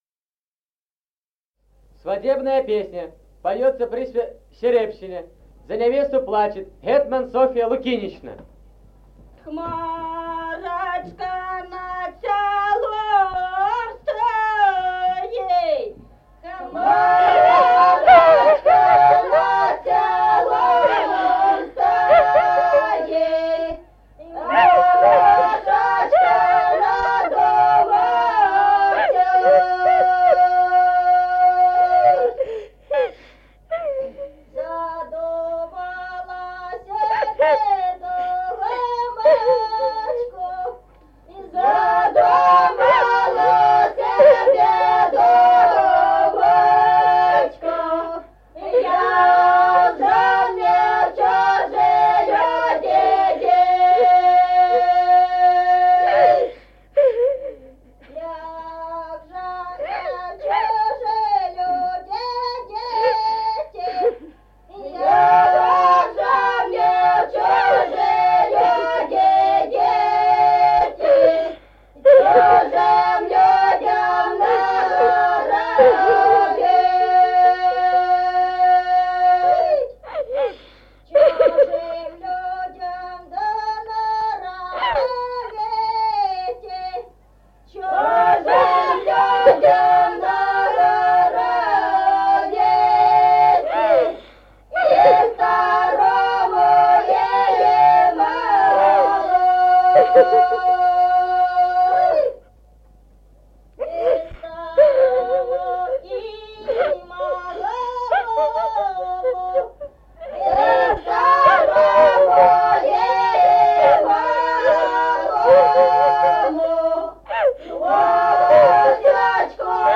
Музыкальный фольклор села Мишковка «Хмарочка над селом стоит», свадебная.